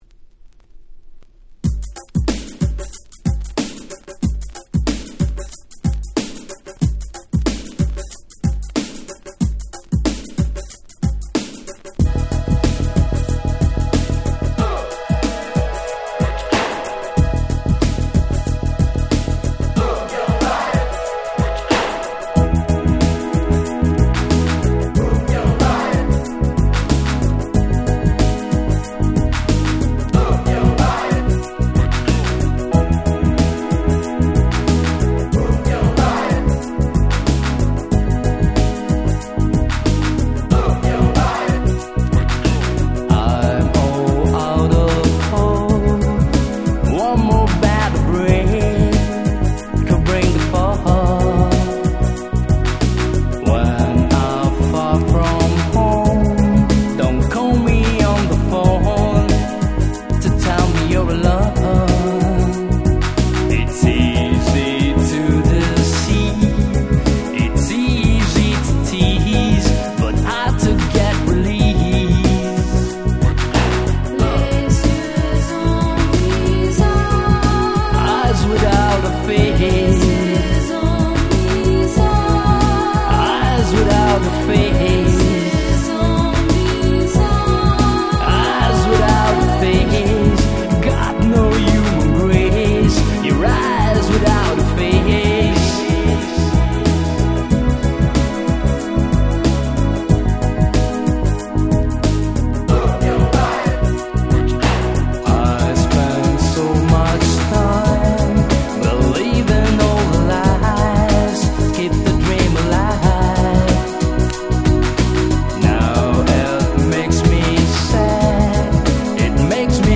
90' Nice Ground Beat !!